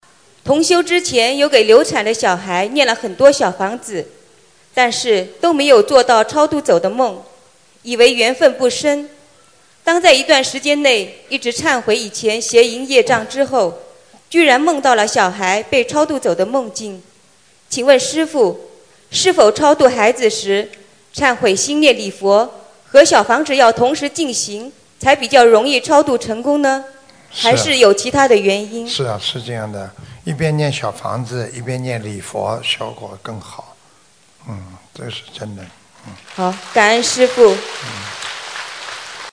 一边念小房子，一边念礼佛大忏悔文，超度效果更好——弟子提问 师父回答--2017年4月马来西亚印尼大型弘法活动（2）博客 2017-05-31 ...